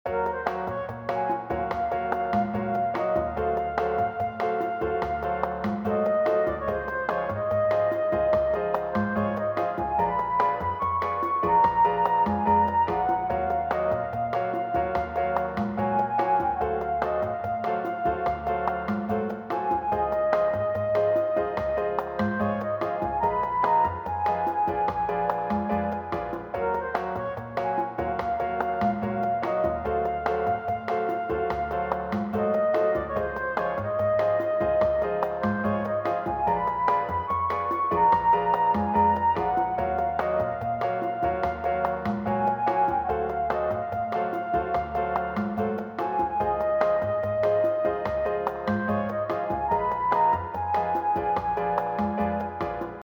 Bossa Nova